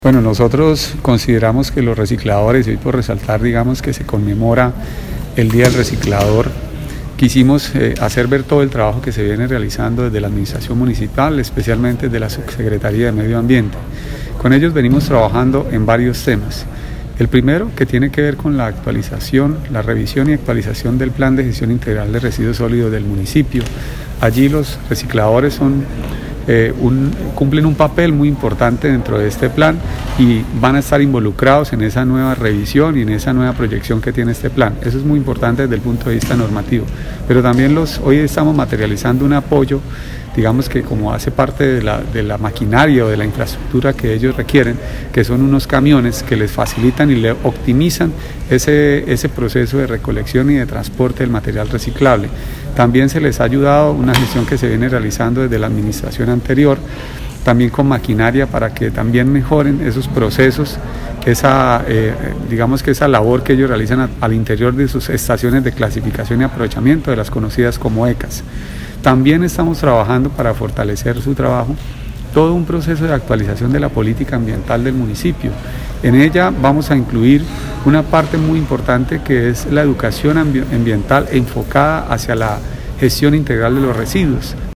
Helbert-Panqueva-subsecretario-de-Medio-Ambiente.mp3